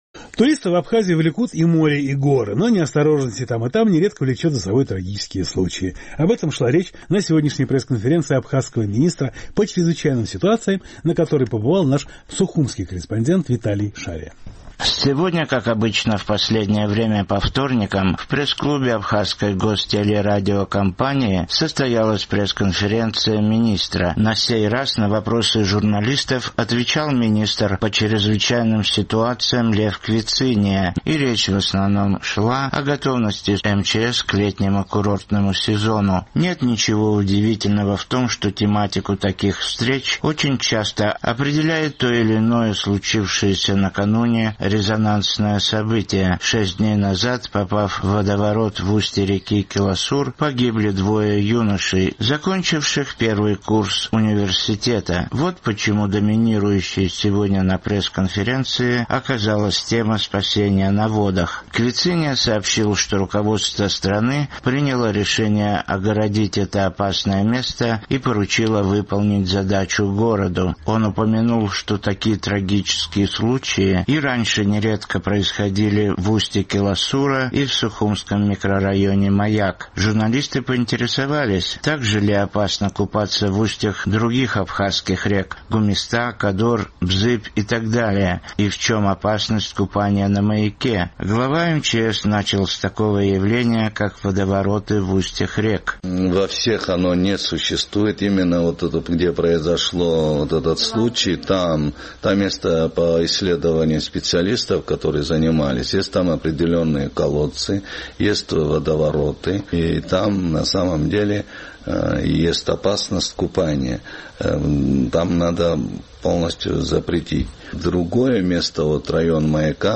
Сегодня, как обычно в последнее время по вторникам, в пресс-клубе Абхазской гостелерадиокомпании состоялась пресс-конференция министра. На сей раз на вопросы журналистов отвечал министр по чрезвычайным ситуациям Лев Квициния, и речь шла о готовности служб МЧС к летнему курортному сезону.